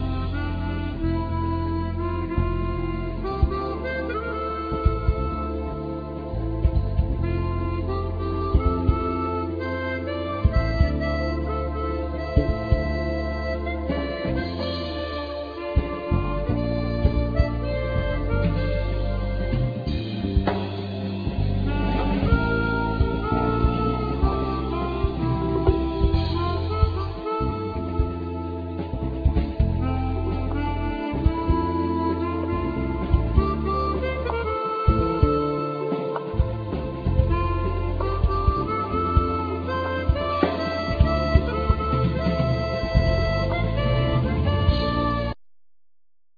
Guitar,Harmonica,Programing,Guitar Synthe
Drums
Bandneon
Vocal
Bass
Keyboards
Piano
DoubleBass
Percussions